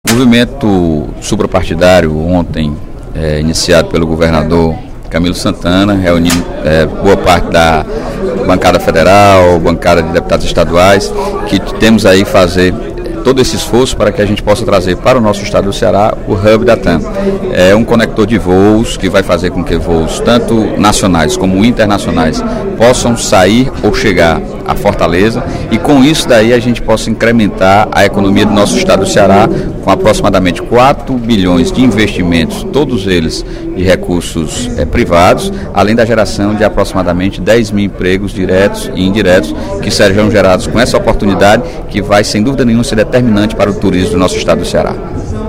O deputado Sérgio Aguiar (Pros) destacou, durante o primeiro expediente da sessão plenária desta terça-feira (23/06), o lançamento da campanha em prol da instalação do HUB da Latam Airlines – formada pela chilena Lan e pela brasileira TAM - em Fortaleza, que foi realizada na manhã desta segunda-feira (22/06), no Palácio da Abolição.